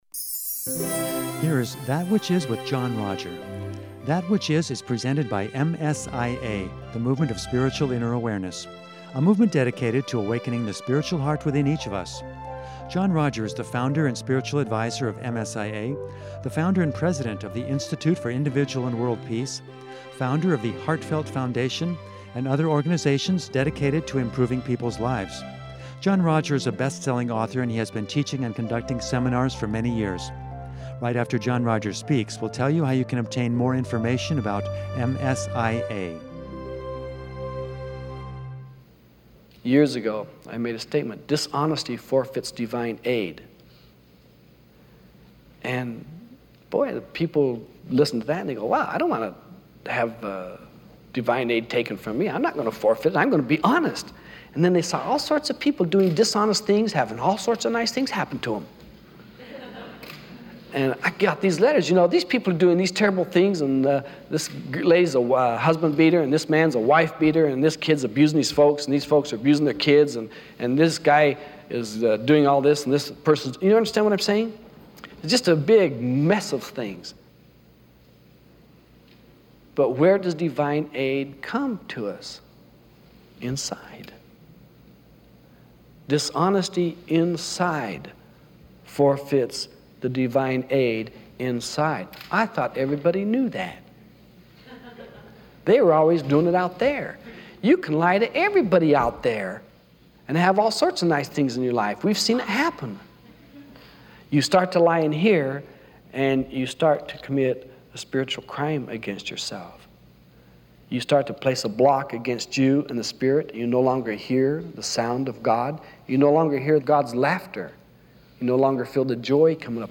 Throughout the second half of this seminar, using physical demonstration, stories and laughter,